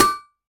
hammer.ogg